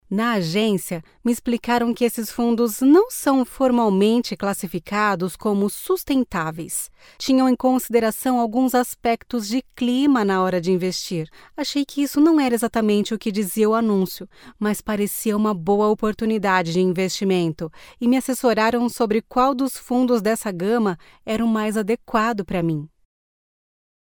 Voces profesionales brasileñas.
locutora Brasil, Brazilian voice over